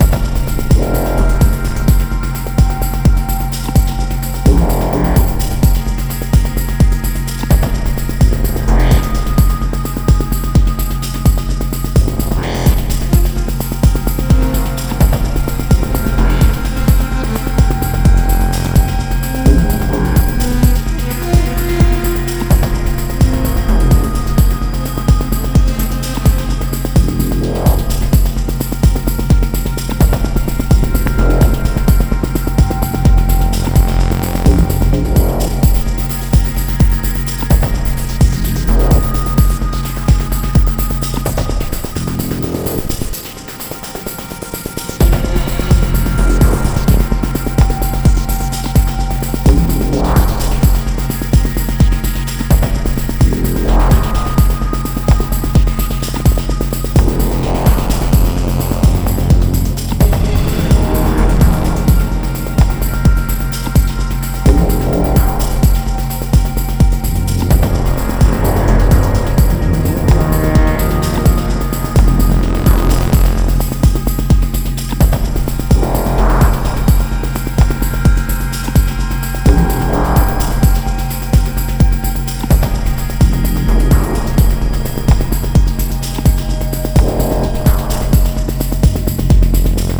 Industrial Techno
Techno Wave EBM